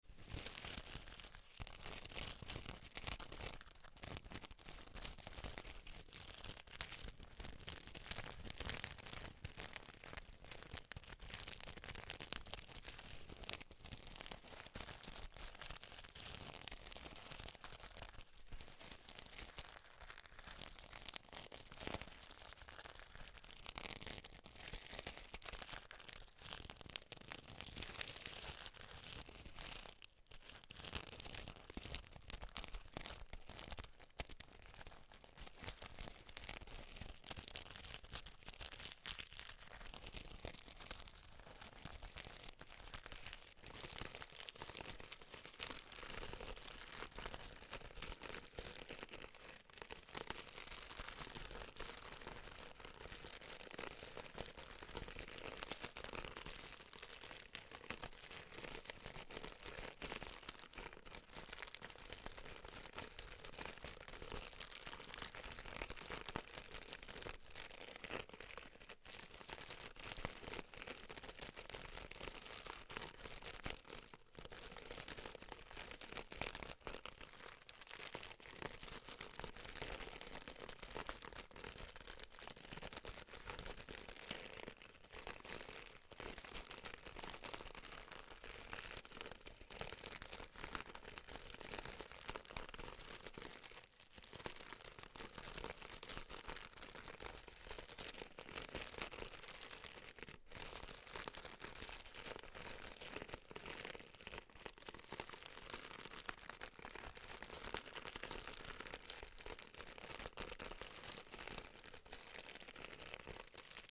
Peace in every scratch, sparkle